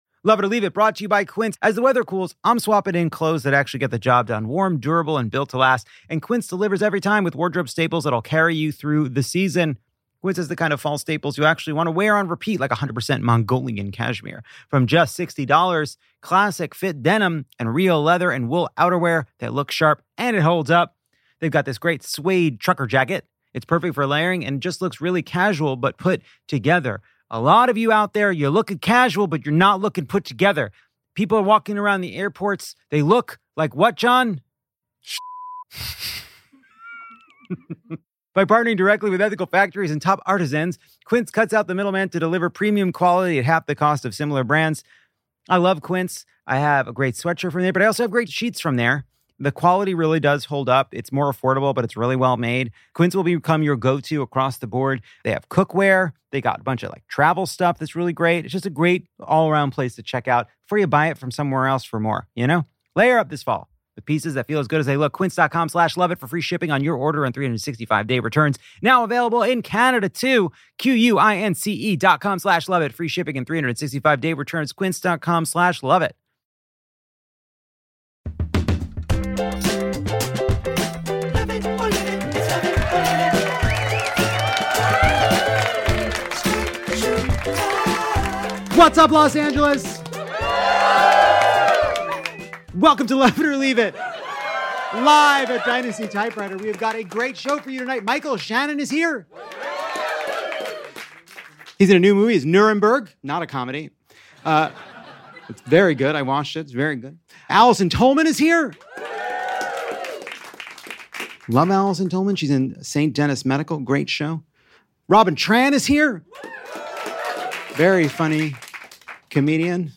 This week, Donald Trump takes a wrecking ball to the White House, ICE recruits gasp for breath during a light jog, and Maine Senate candidate Graham Platner re-inks his past. Acclaimed and nearly interchangeable actors Michael Shannon and Allison Tolman grace us with stories about Nuremberg and fake blood gone wrong.